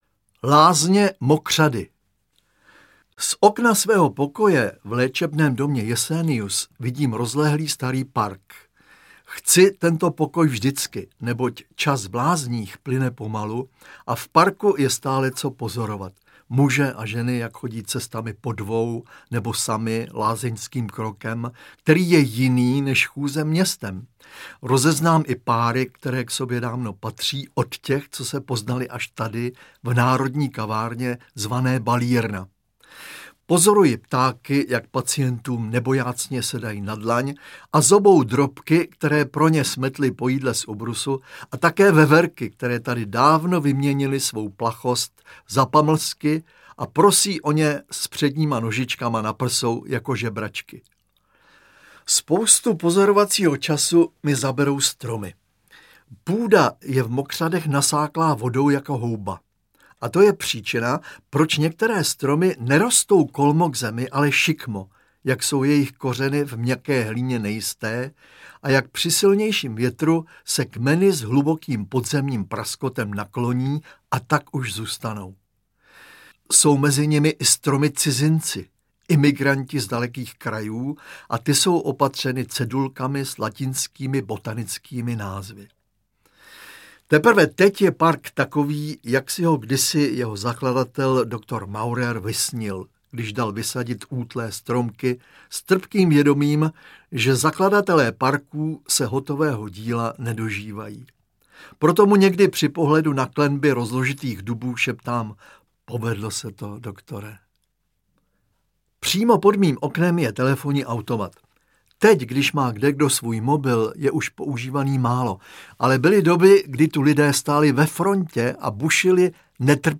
Interpreti:  Daniela Kolářová, Zdeněk Svěrák